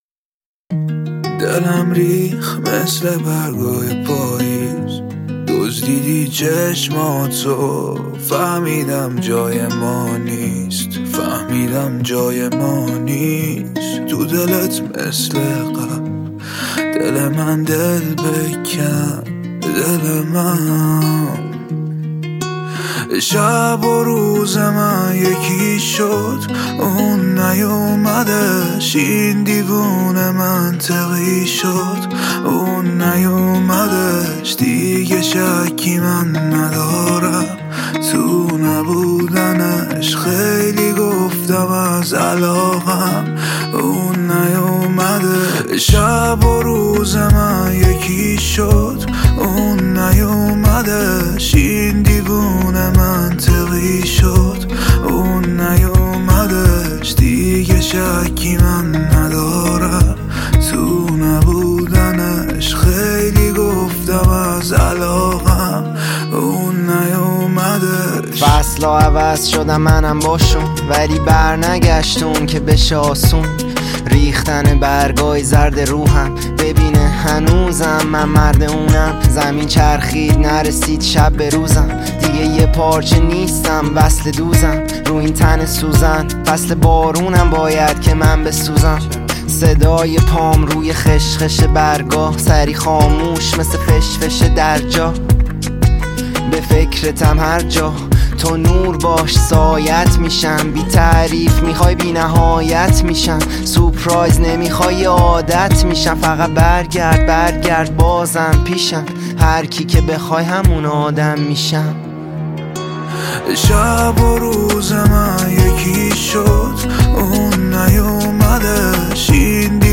دانلود آهنگ جدید ایرانی پاپ
دانلود آهنگ ریمیکس رپ